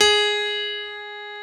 Index of /90_sSampleCDs/Keyboards of The 60's and 70's - CD2/PNO_E.Grand/PNO_E.Grand